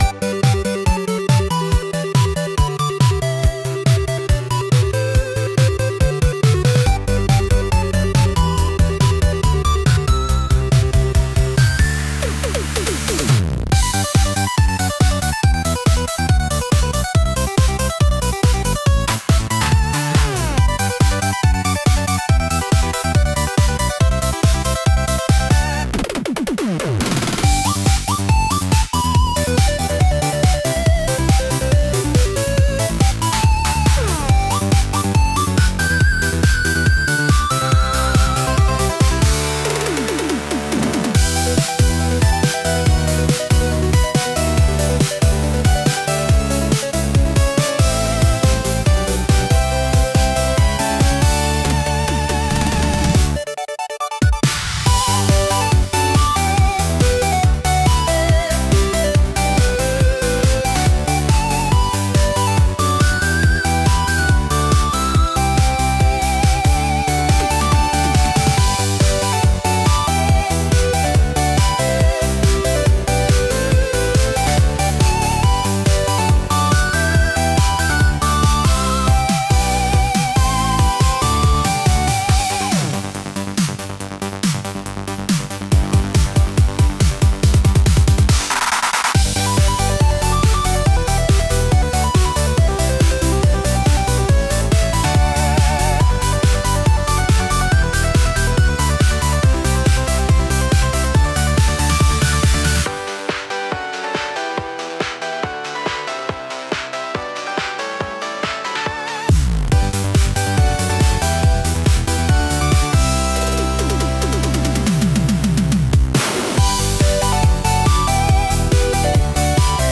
キャッチーなメロディラインが爽快感を演出し、厚みのあるベースとドラムが楽曲全体の推進力を高めています。
• ジャンル： EDM / ダンス・ポップ / シンセ・ポップ
• 雰囲気： エネルギッシュ / 煌びやか / パワフル / 希望 / 疾走感
• テンポ（BPM）： アップテンポ（高揚感のある速さ）